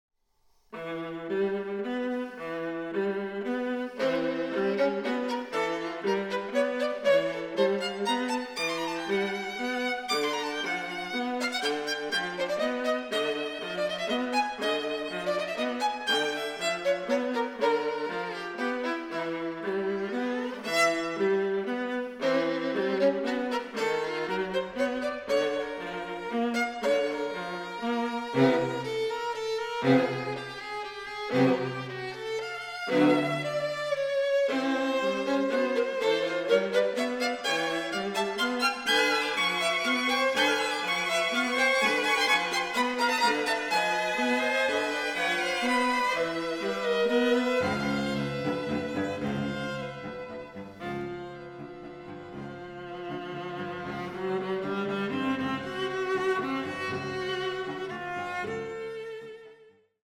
Recording: Festeburgkirche Frankfurt, 2024